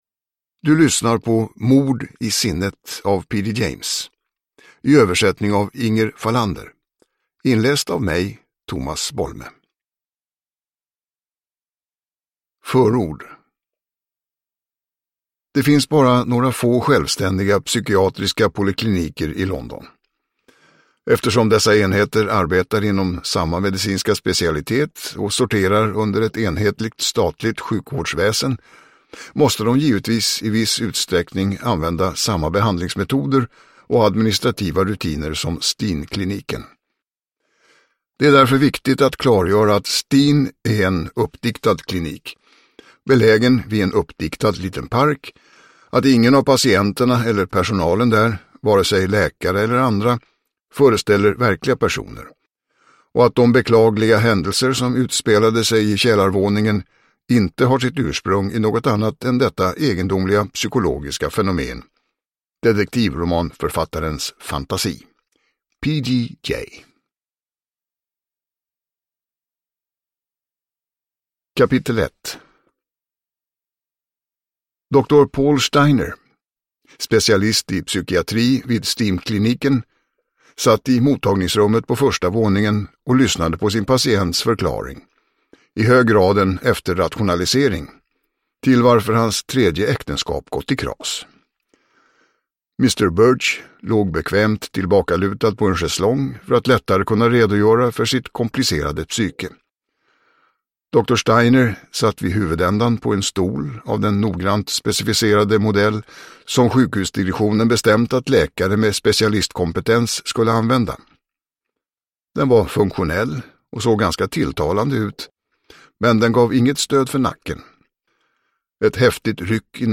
Uppläsare: Tomas Bolme